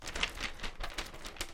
Aleteo
描述：Aleteo de patos。 Grabado con ZoomH4n
Tag: 翅膀